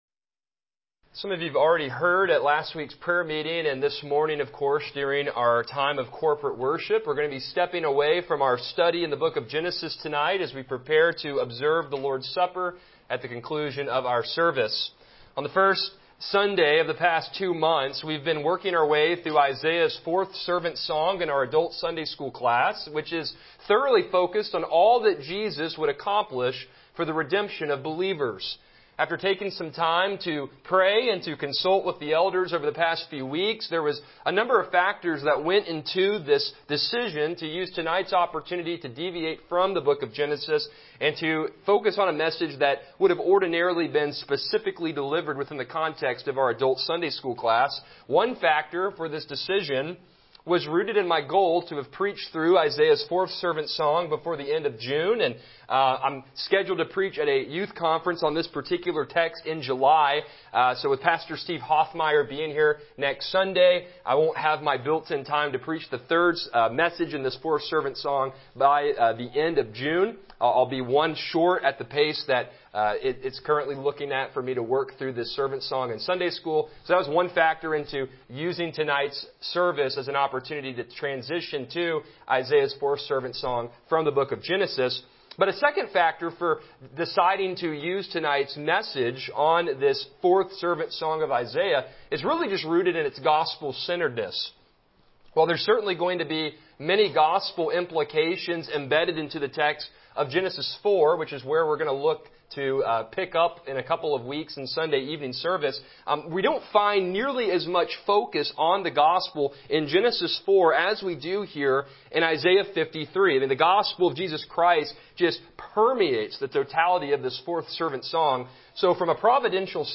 Passage: Isaiah 53:10-12 Service Type: Evening Worship